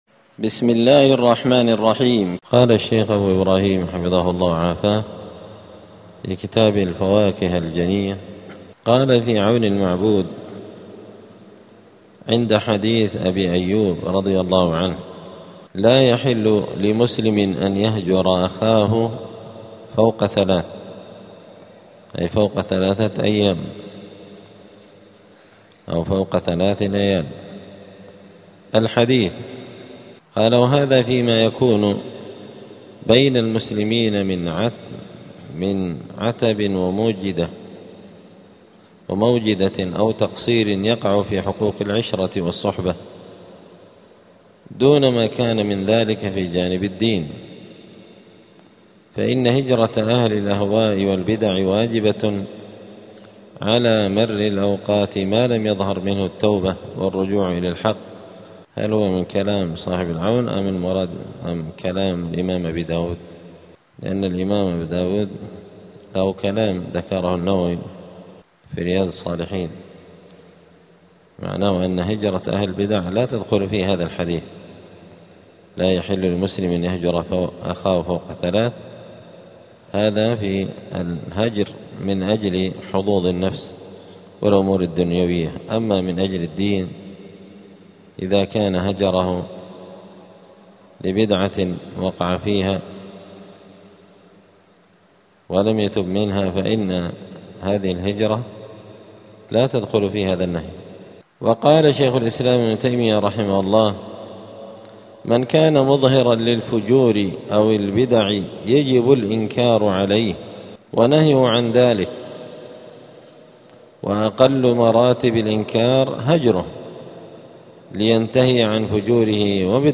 دار الحديث السلفية بمسجد الفرقان بقشن المهرة اليمن
الدروس الأسبوعية